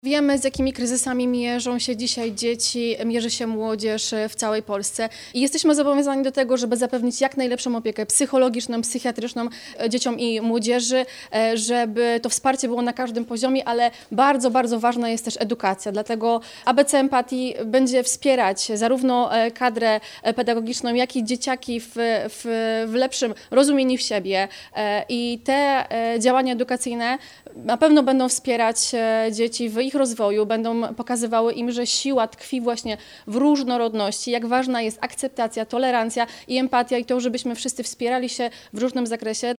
Na każdym szczeblu – samorządowym i rządowym – mówi Jolanta Niezgodzka, posłanka na Sejm, członkini Dolnośląskiej Rady Kobiet.